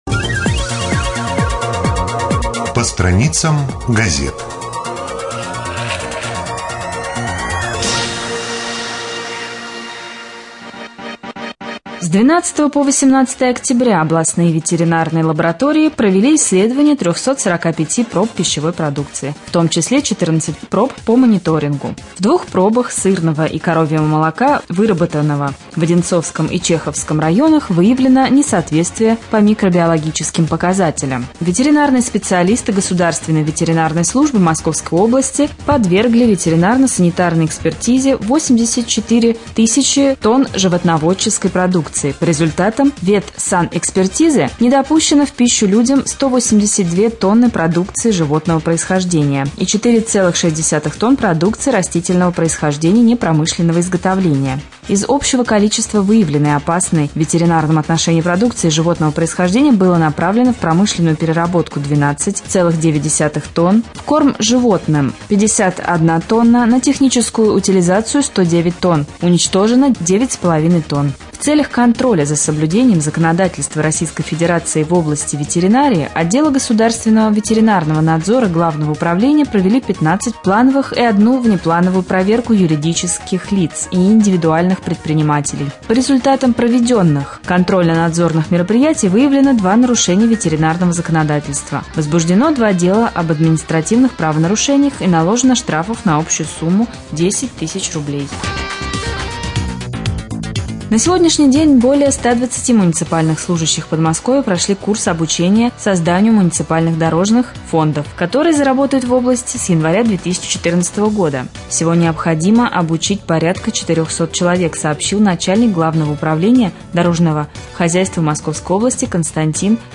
1.Новости.mp3